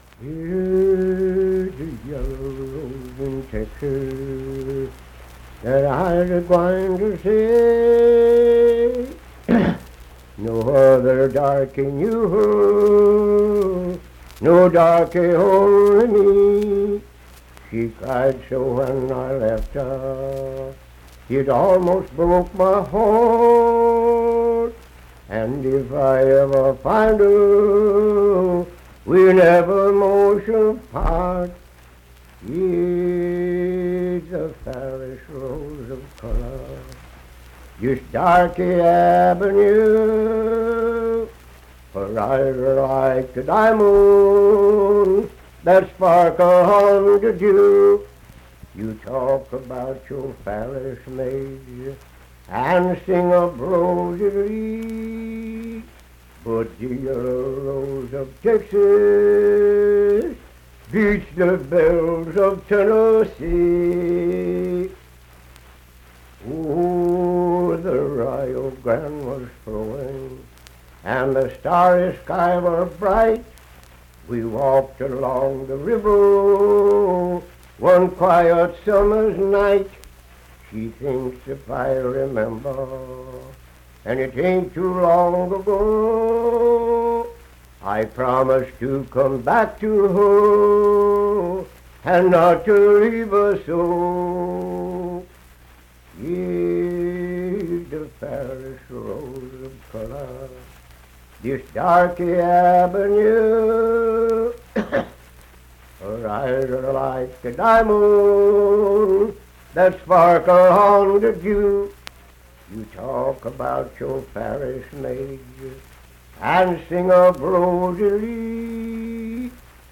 Unaccompanied vocal music performance
Love and Lovers, Minstrel, Blackface, and African-American Songs
Voice (sung)
Birch River (W. Va.), Nicholas County (W. Va.)